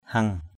heng.mp3